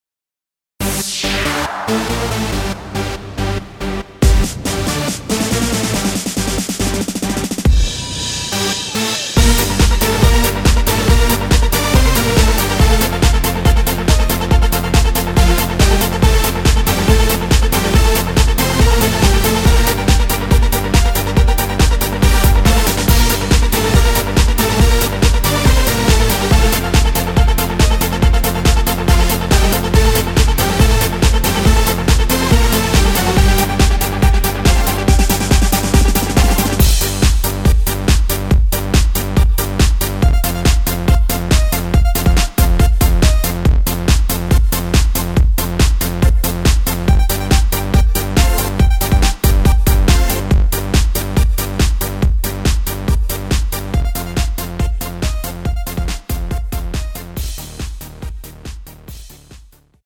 원키에서(-3) 내린 MR 입니다.(미리듣기 참조)
Gm
앞부분30초, 뒷부분30초씩 편집해서 올려 드리고 있습니다.
중간에 음이 끈어지고 다시 나오는 이유는